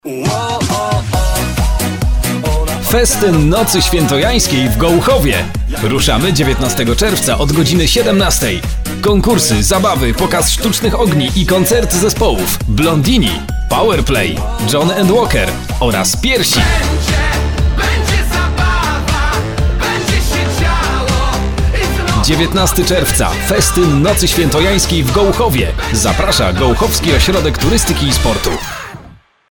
Reklama: Fetyn 2014[807.17 KB]
reklama.mp3